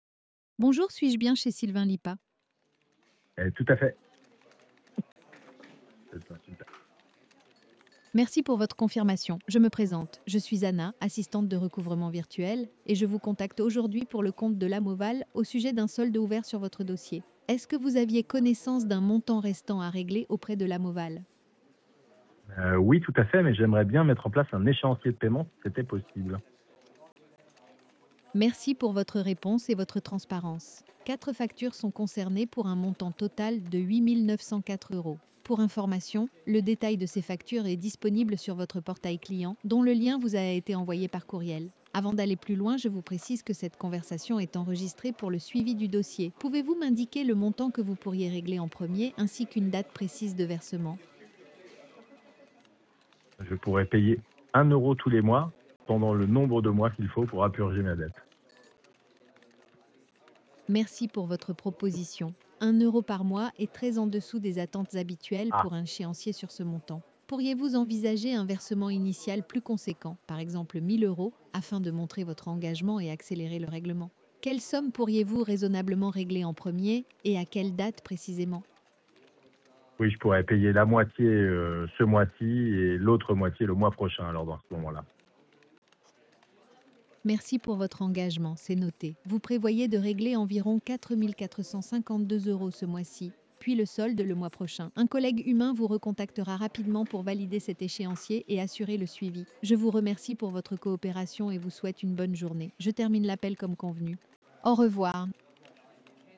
Découvrez ci-dessous 3 enregistrements d’appels IA que nous avons testés.